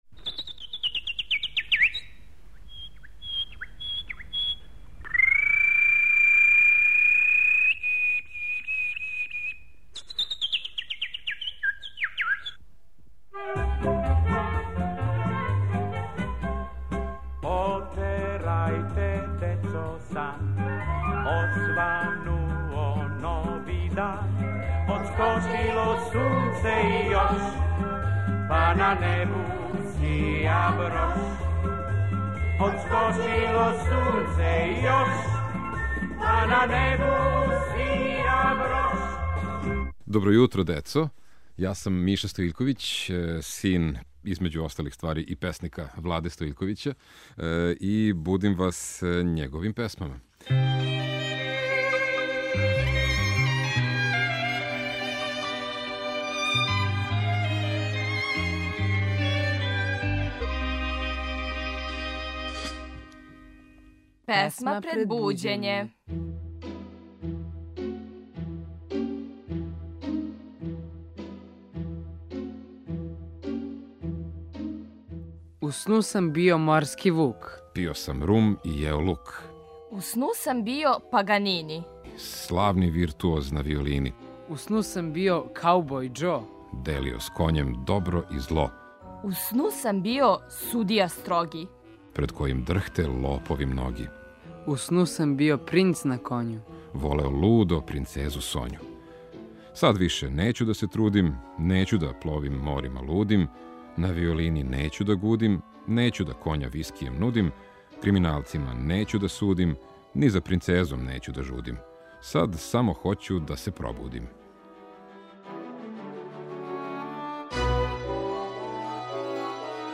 чита поезију